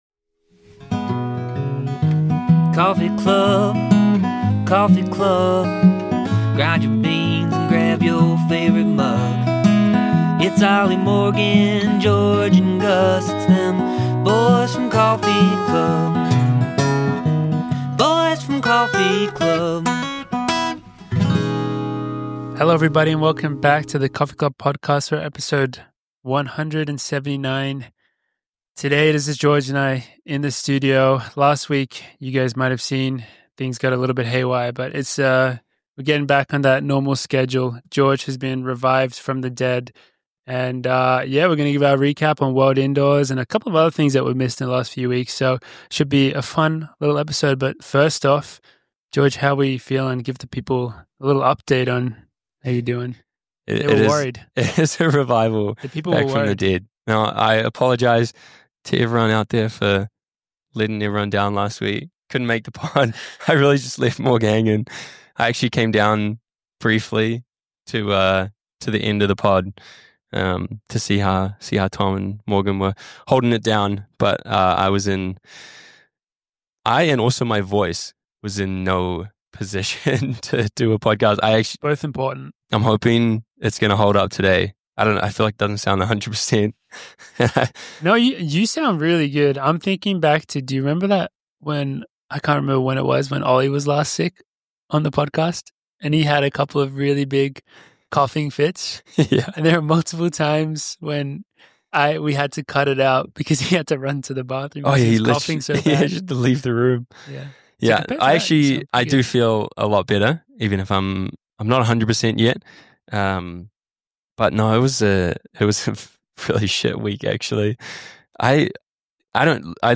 A few runner bois (Morgan McDonald, Oliver Hoare, and George Beamish) sitting down drinking coffee and having a chat.